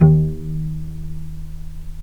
vc_pz-D#2-pp.AIF